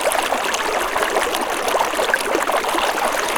water sounds nice.
fountain-2.wav